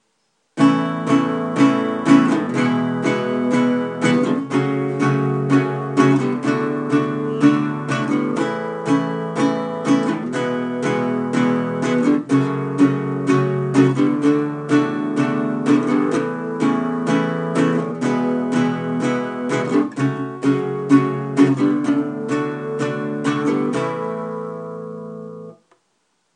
The ‘I-V-vi-IV’ in A
FAQ-Progression-in-A-1.mp3